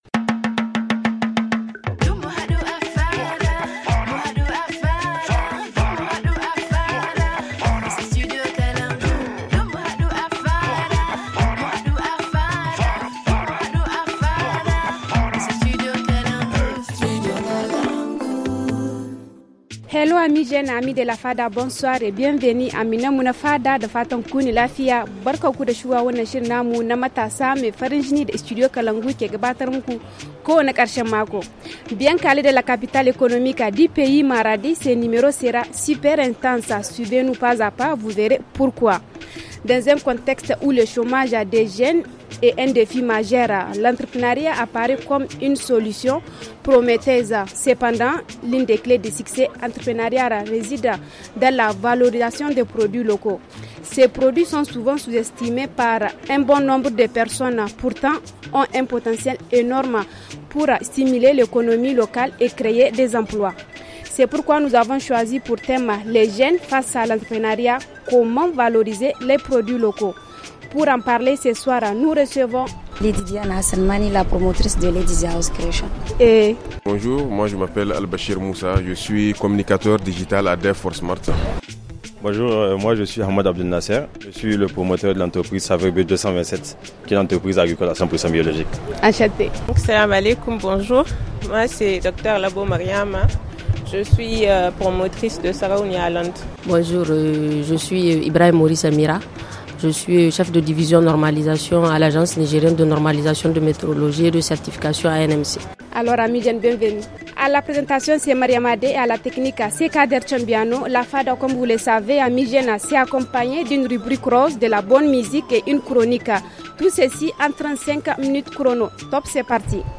Ce soir, l’équipe jeune du Studio Kalangou est à Maradi pour la 8ème édition du FONAF, où les jeunes s’expriment sur l’entrepreneuriat et la valorisation des produits locaux.